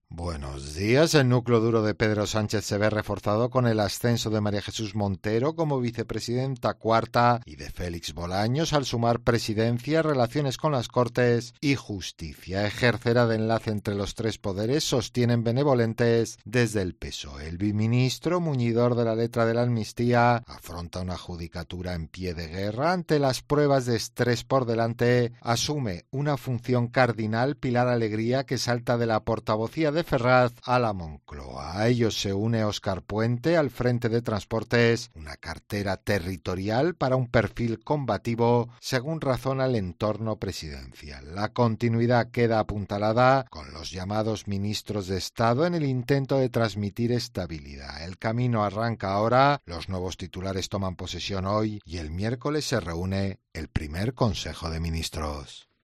Carlos Herrera, director y presentador de 'Herrera en COPE', comienza el programa de este miércoles analizando las principales claves de la jornada que pasan, entre otras cosas, el desahucio de Pedro Sánchez a Irene Montero, Ione Belarra y Podemos.